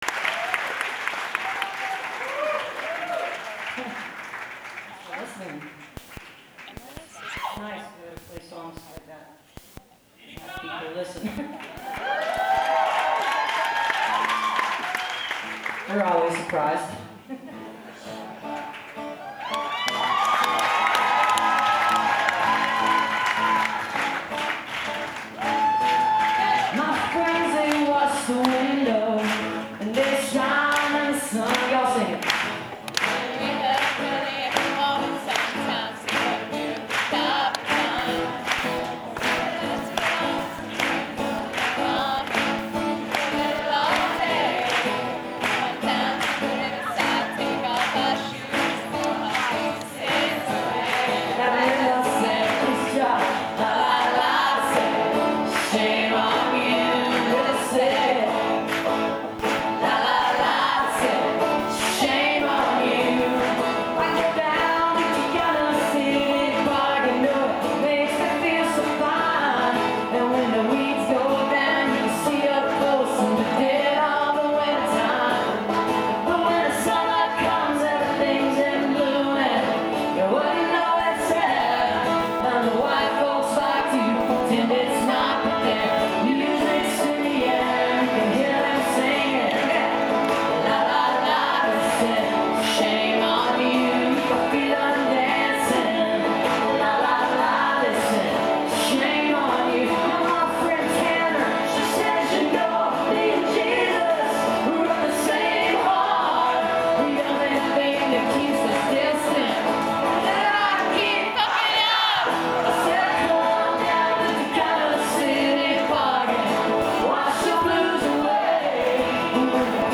zankel hall - carnegie (acjw) - new york, new york